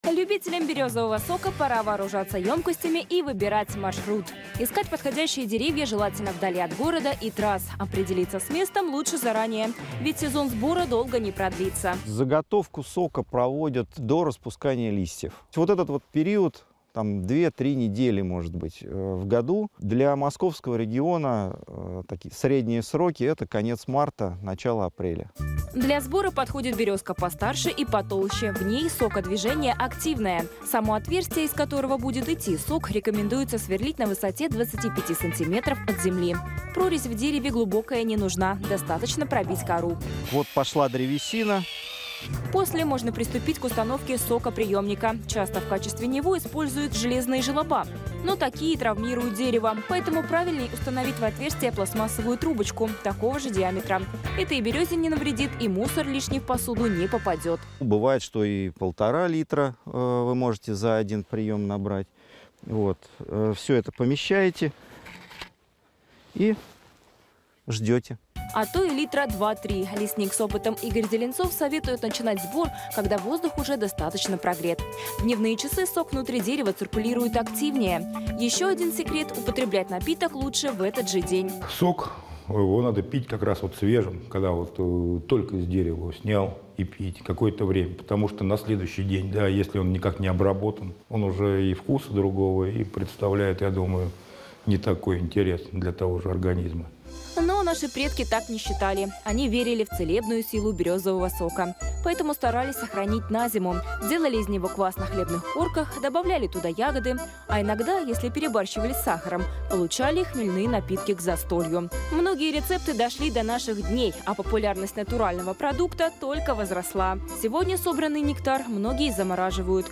30.03.2021 Источник: телеканал ТВЦ Участники
2:55 вернуться к медиатеке скачать интервью в mp3 формате Всем здорового духа и тела!